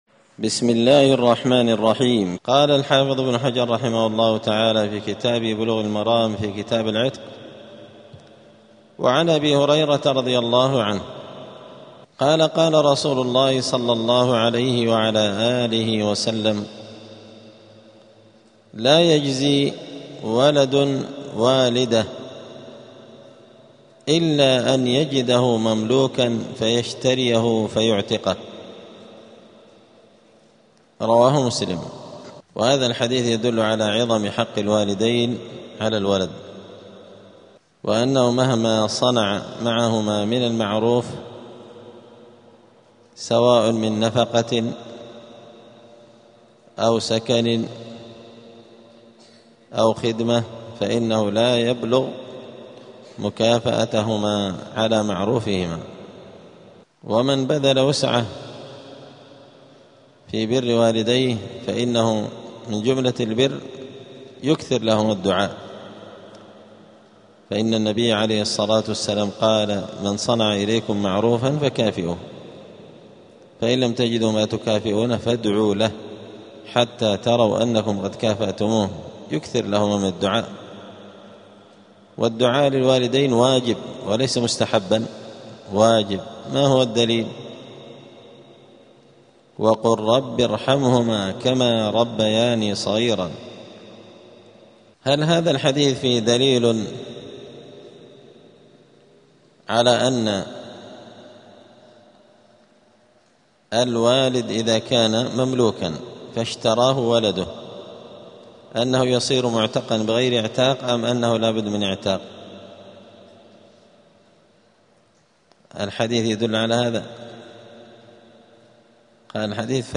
*الدرس الثالث (3) {ﻣﻦ ﺃﻋﺘﻖ ﺣﻈﻪ ﻣﻦ ﻋﺒﺪ ﻋﺘﻖ ﻋﻠﻴﻪ ﻛﻞ اﻟﻌﺒﺪ}*
دار الحديث السلفية بمسجد الفرقان قشن المهرة اليمن